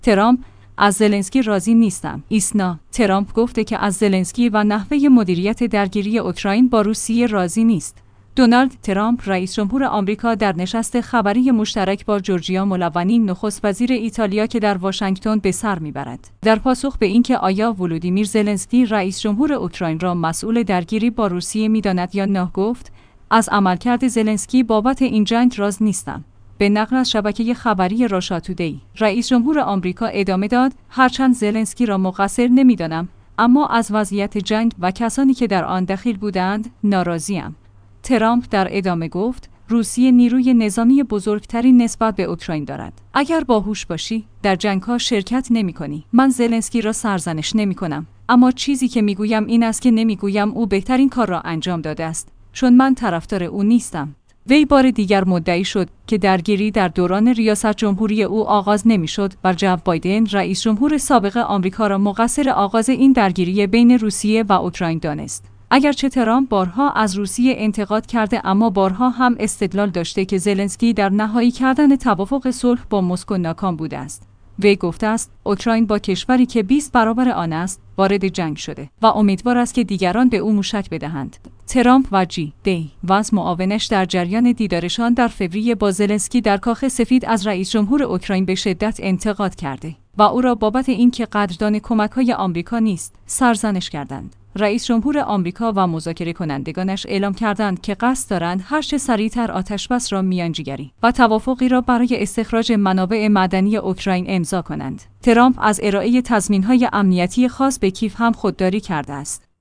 ایسنا/ ترامپ گفته که از زلنسکی و نحوه مدیریت درگیری اوکراین با روسیه راضی نیست. «دونالد ترامپ» رئیس جمهور آمریکا در نشست خبری مشترک با «جورجیا ملونی» نخست وزیر ایتالیا که در واشنگتن به سر می‌برد، در پاسخ به اینکه آیا «ولودیمیر زلنسکی» رئیس جمهور اوکراین را مسئول درگیری با روسیه می‌داند یا نه گفت: «ا